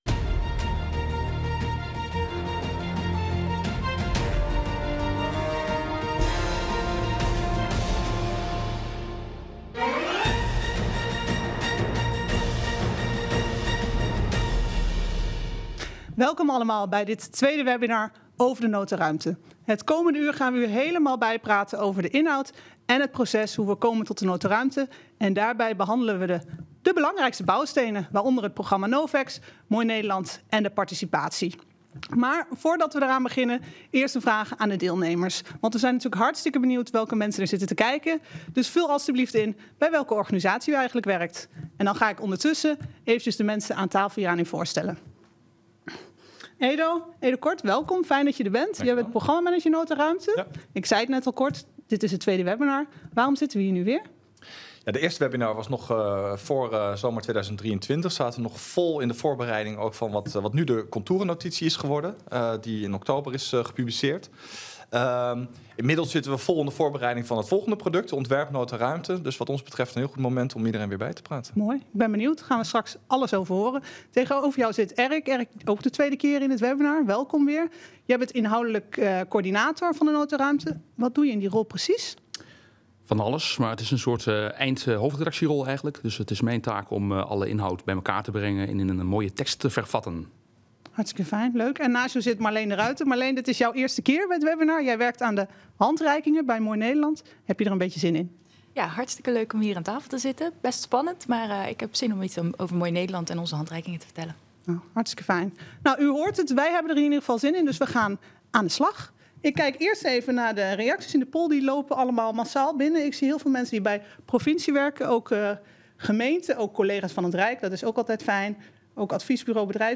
Webinar Nota Ruimte (25 januari 2024)